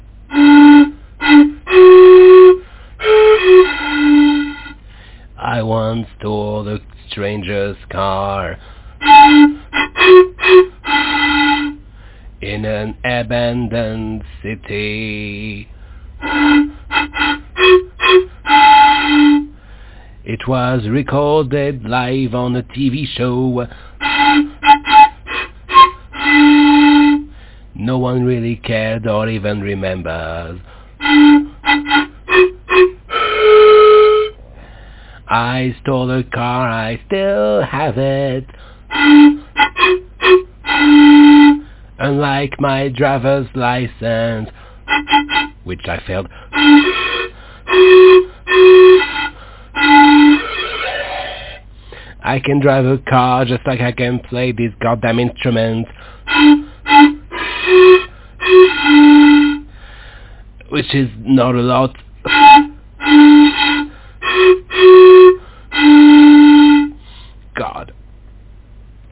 • The instrument is a pan flute, believe it or not.